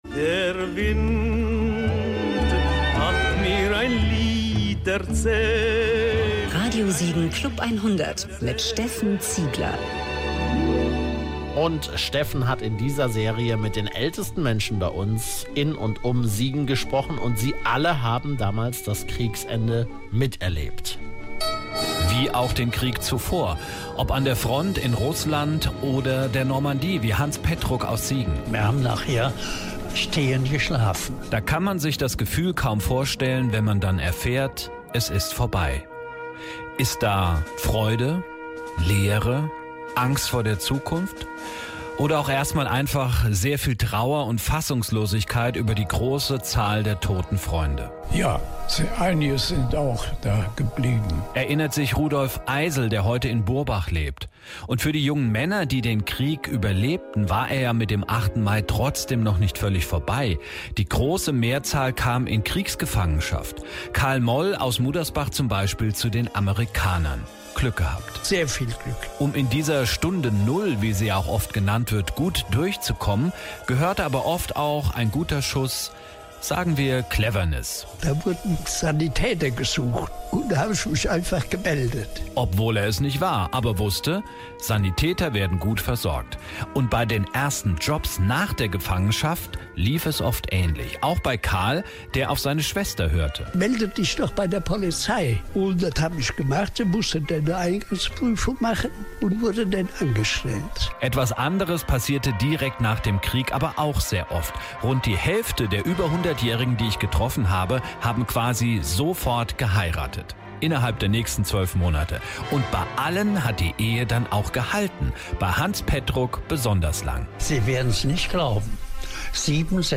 Wir haben mit Zeitzeugen gesprochen - Hört gerne in unsere Berichte rein.